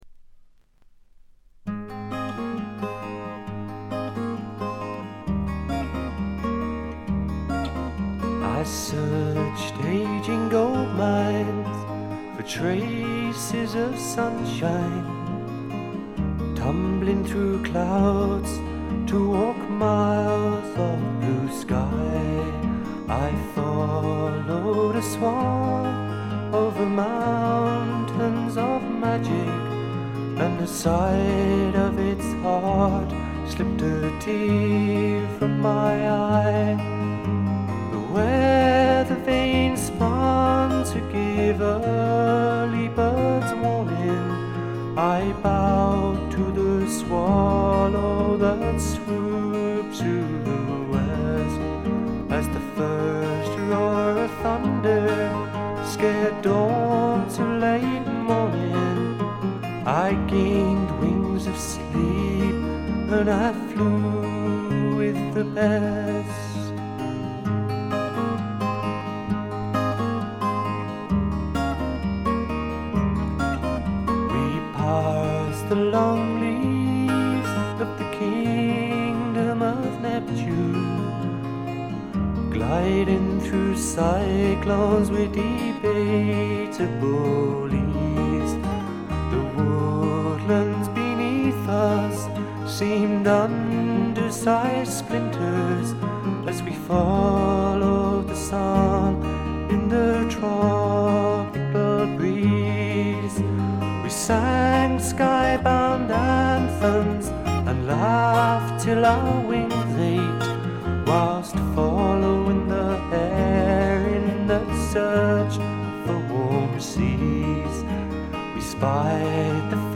そんなわけでソロになった本作ではまさにマイルドでジェントルなフォーク／フォーク・ロック路線が満開です。
試聴曲は現品からの取り込み音源です。